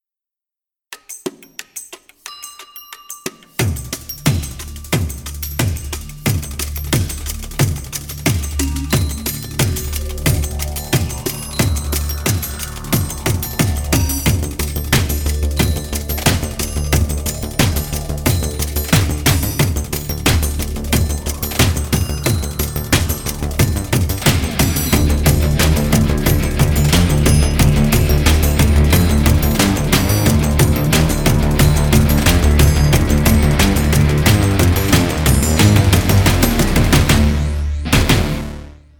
Strezov Sampling Lightning X3M是一款有机和电子高频打击乐器，基于Strezov Sampling的著名的X3M打击乐引擎，专为KONTAKT设计。
从钟表、沙锤、铅笔、风琴、电子音到水晶玻璃，Lightning X3M为您提供了一个丰富多样的高频打击乐库。
该库具有多个动态级别（从非常柔和的ppp到爆破ffff），并具有多个循环（这意味着每个乐器都有独特的样本，每次按下某个调时都会更改）。